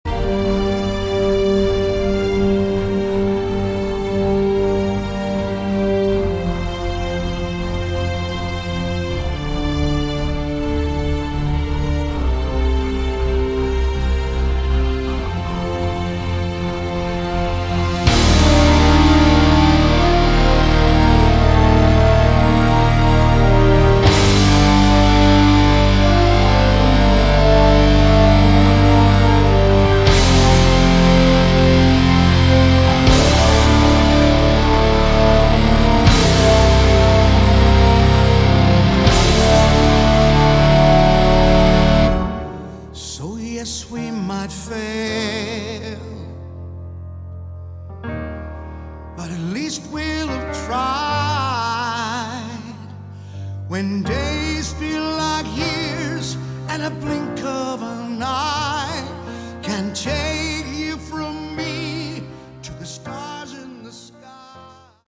Category: Hard Rock
drums